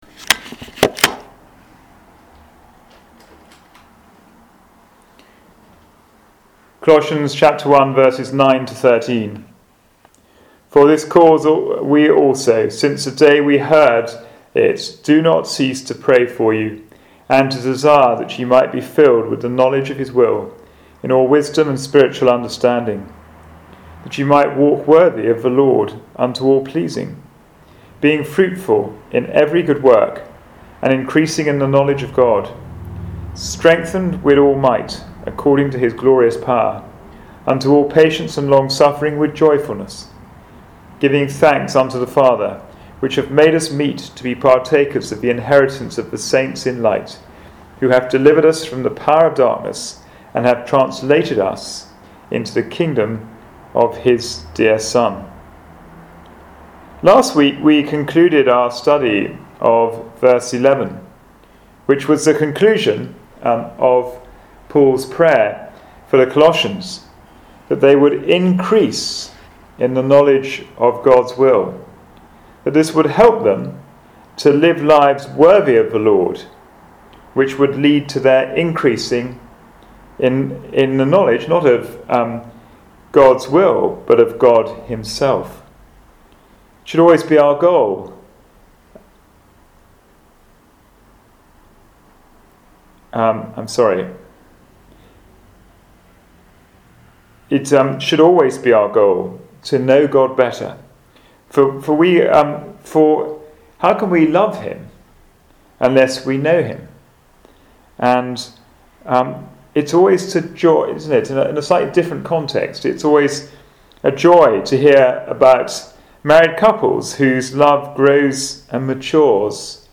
Passage: Colossians 1:12-13 Service Type: Wednesday Bible Study